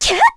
Erze-Vox_Attack3_kr.wav